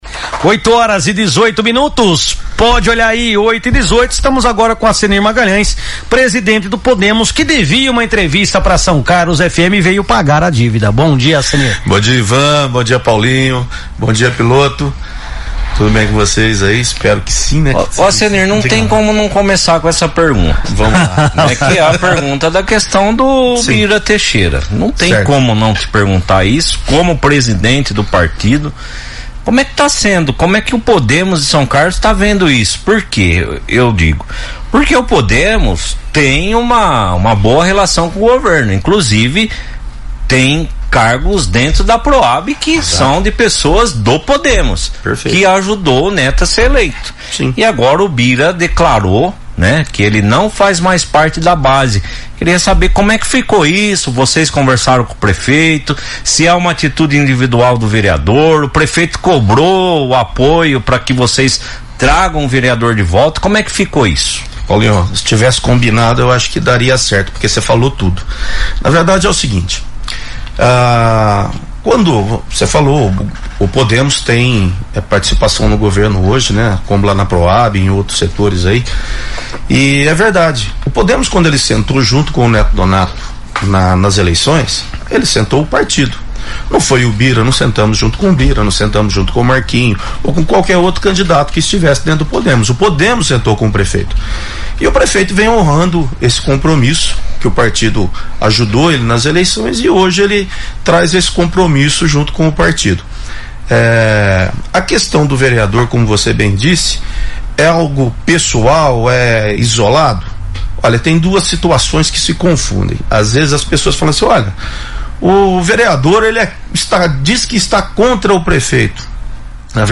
Em entrevista ao Primeira Página no Ar, da São Carlos FM